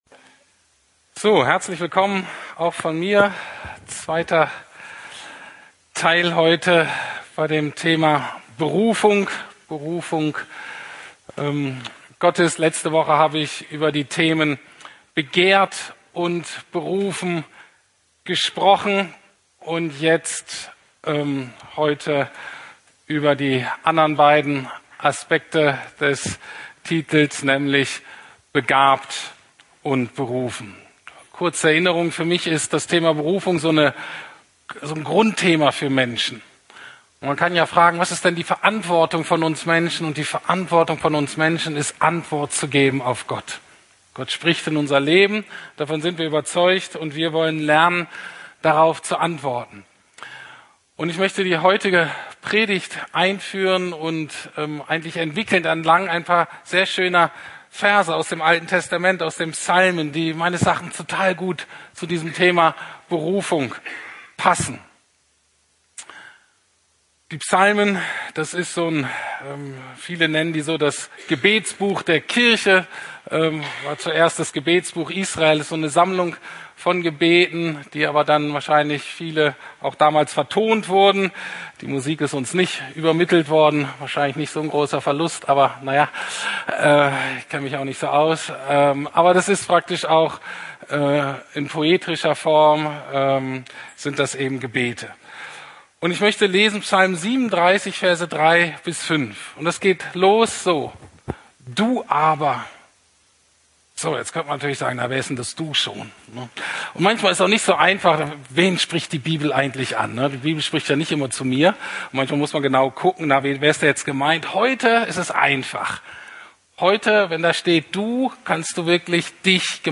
Begehrt, Begabt, Berufen - Teil 2 ~ Predigten der LUKAS GEMEINDE Podcast